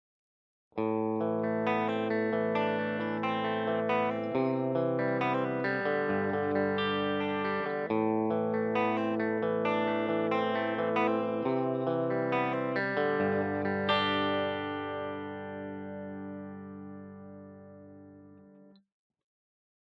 Das Aufnahmesetup ist dasselbe wie bei der Paula.
60er Fender Telecaster, Singlecoil Steg:
Es handelt sich zweifelsohne um eine Telecaster.
di_fender_60er_telecaster_b.mp3